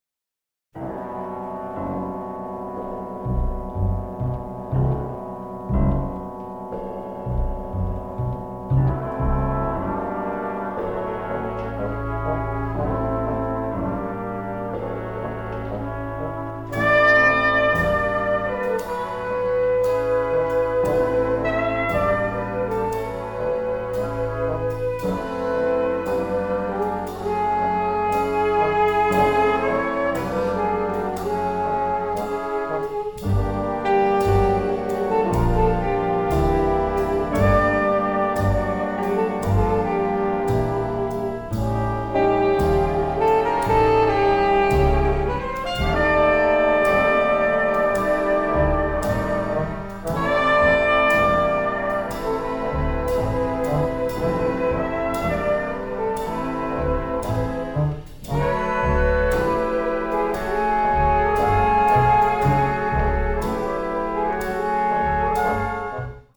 composed for jazz ensembles